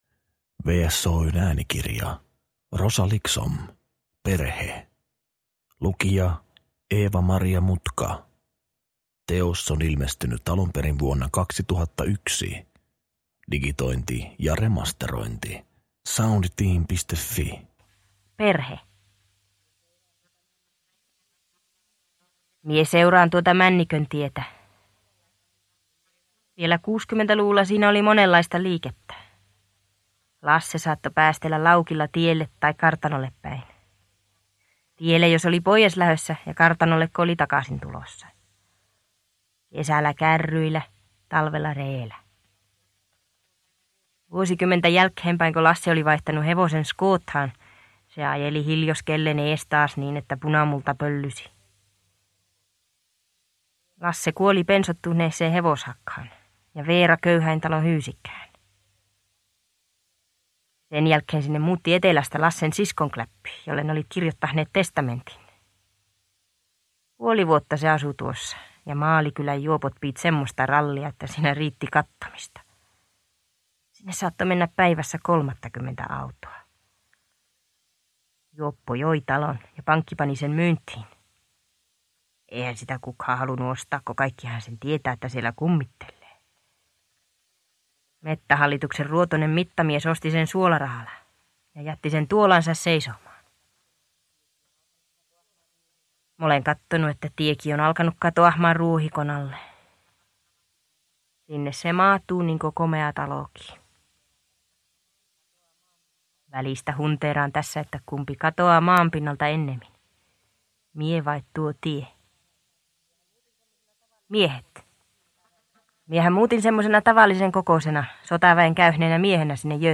Perhe – Ljudbok – Laddas ner